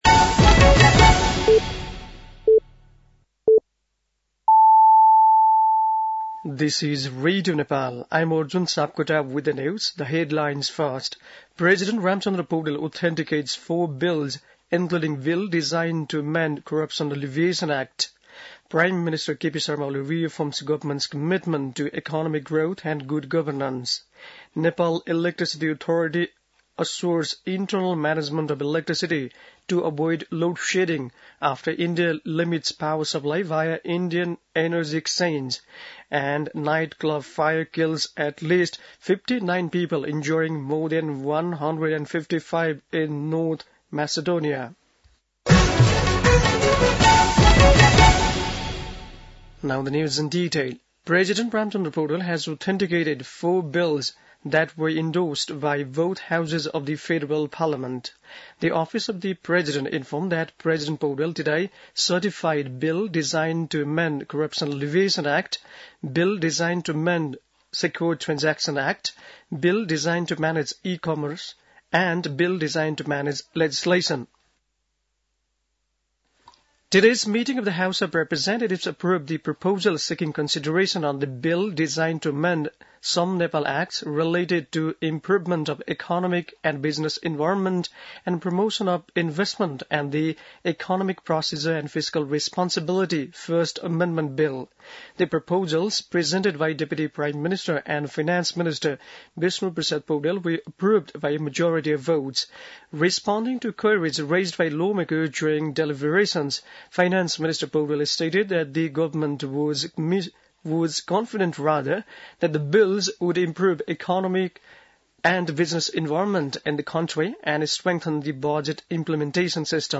बेलुकी ८ बजेको अङ्ग्रेजी समाचार : ३ चैत , २०८१
8-pm-english-news-12-03.mp3